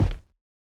added stepping sounds
BootsLinoleum_02.wav